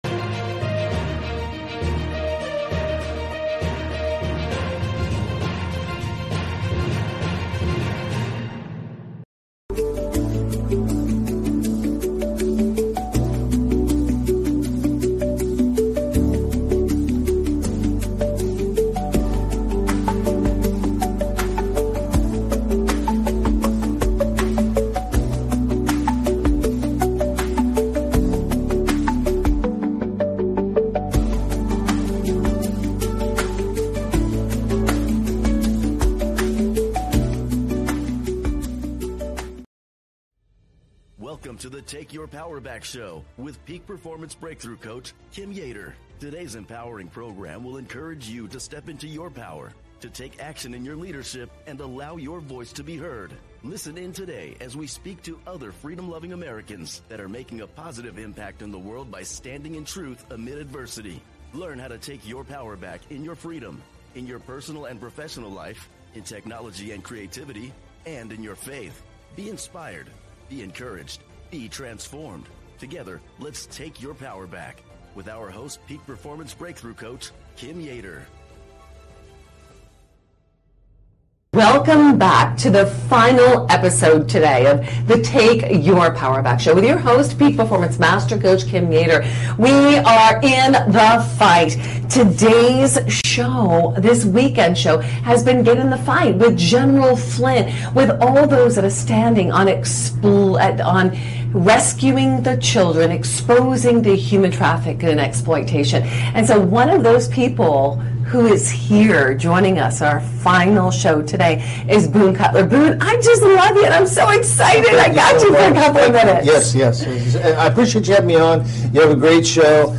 The show features discussions with freedom-loving Americans who are positively impacting the world by standing up for truth. Topics include personal and professional freedom, technology, creativity, and faith.